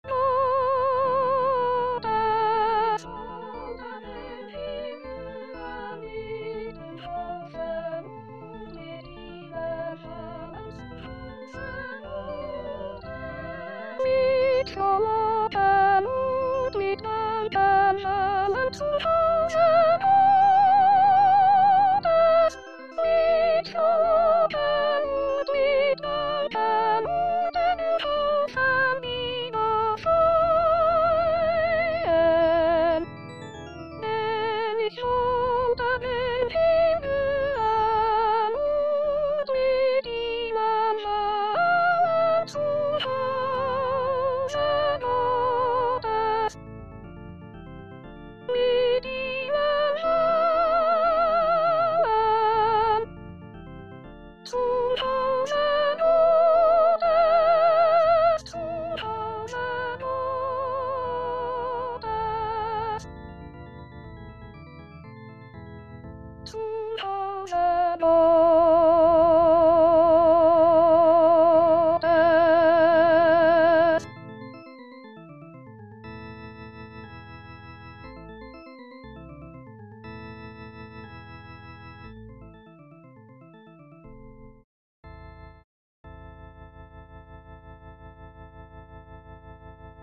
S_Solo
MH3-B_S Solo.mp3